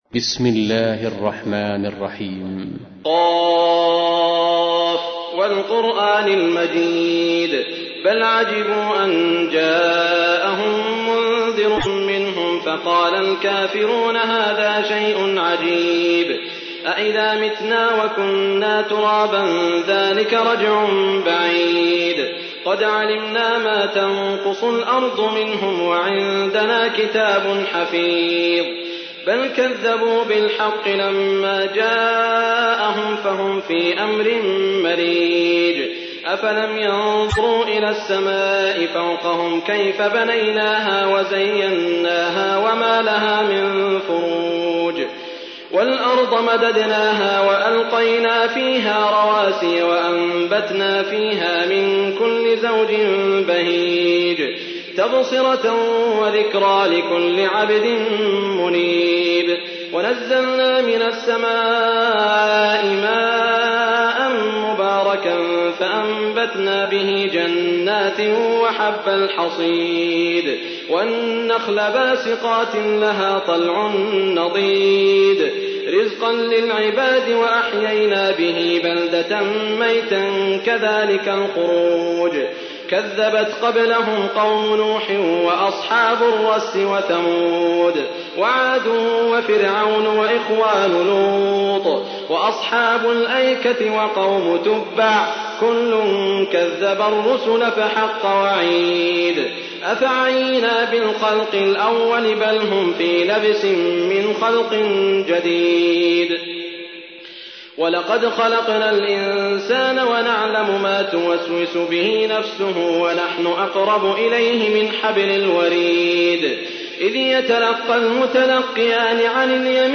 تحميل : 50. سورة ق / القارئ سعود الشريم / القرآن الكريم / موقع يا حسين